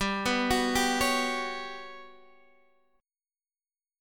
Listen to GM#11 strummed